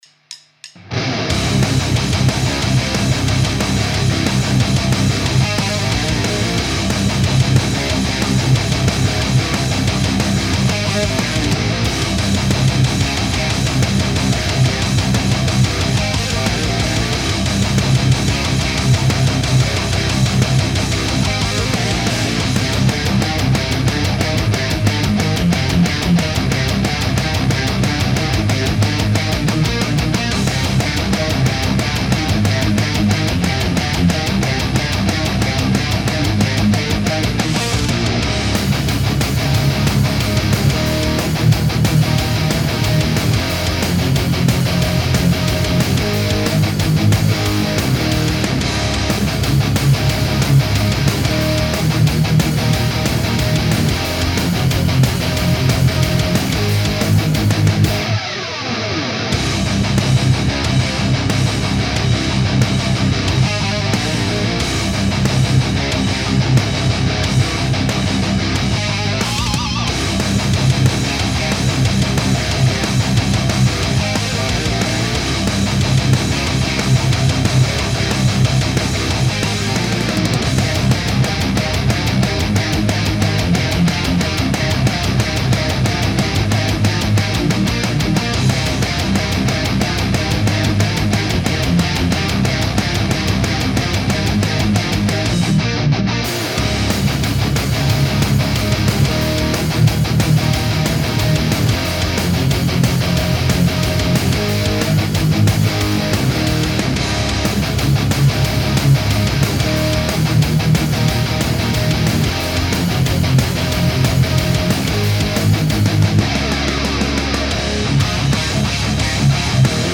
gerade mal der erste Reamp durch den VHT.....
hier ist mal ein kleines Beispiel...das ist ohne viel am Amp gedreht zu haben, einfach ein sm57 vor den Speaker (nichtmal auf bestimmter Position) und an....
natürlich keinerlei NAchbearbeitung auf den Gitarren, also auch kein Highpass/Lowpass oder so...blankes Signal vom sm57....
Das BAcking sind leider nur programmierte Drums...butze ich nur zum Testen von Amps, es ist also kein "mix" in irgendeiner Form, von daher könnt ihr euch Kommentare zum Mix/Sound der Drums auch sparen;)...
klingt sehr fett.
Wahnsinn was das für eine Soundwand ist :D